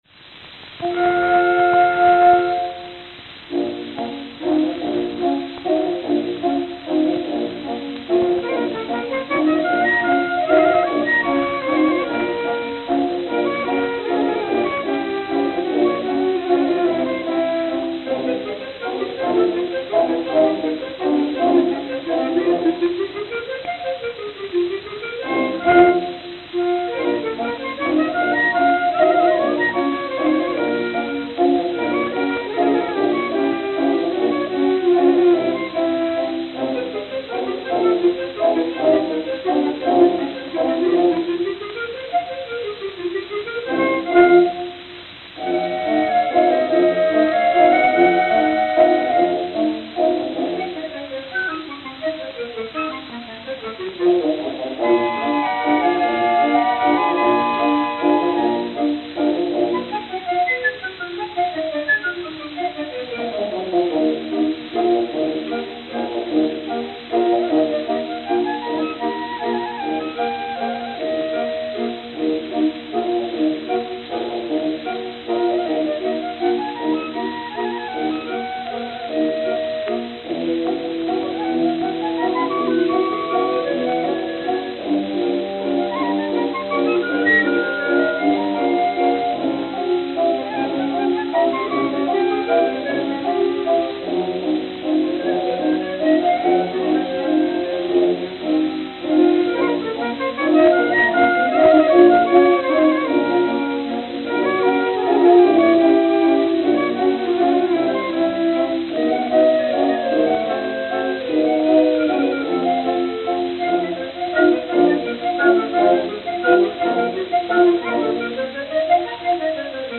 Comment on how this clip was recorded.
Camden, New Jersey Note: In-studio bump at 2:08. Worn.